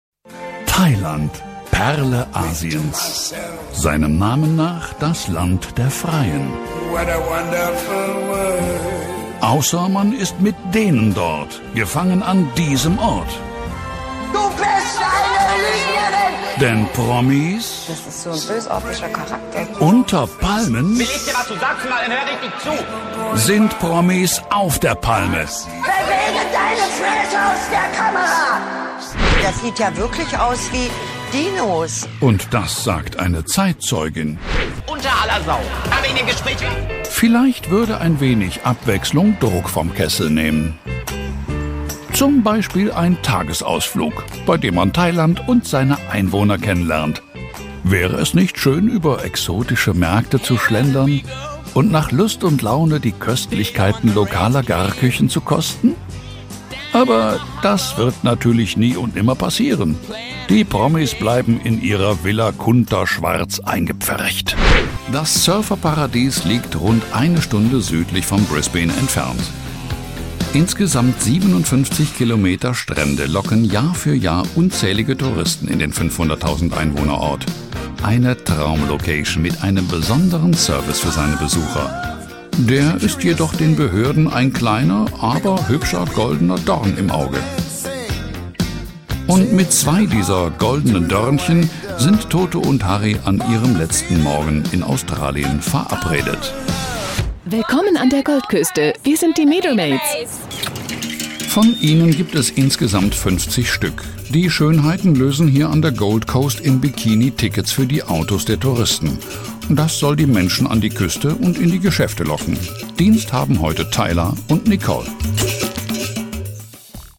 Bekannte, dynamische, kräftige und markante Stimme; mit hohem Wiedererkennungswert in voller warmer Stimmlage.
Professioneller deutscher Sprecher (seit 1994) mit eigenem Studio. Bekannte Stimme (TV/RF), markant mit hohem Wiedererkennungswert.
Sprechprobe: Sonstiges (Muttersprache):
TV-Off--Collage_Projekt.mp3